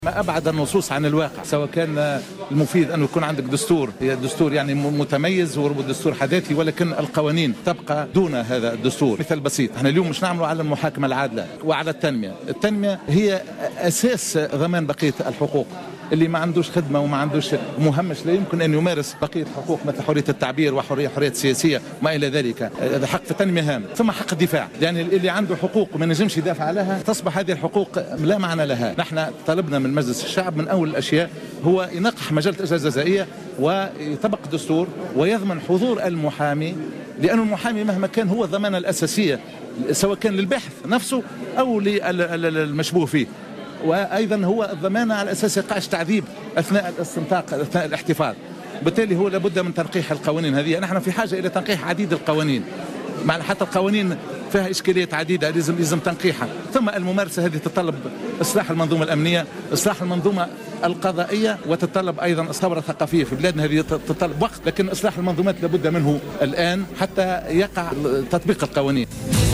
La Ligue Tunisienne pour la Défense des Droits de l'Homme (LTDH), et l'Ordre national des avocats, ont organisé une conférence à l’occasion du 66ème anniversaire de la Déclaration universelle des droits de l’homme (DUDH), organisé chaque année le 10 décembre.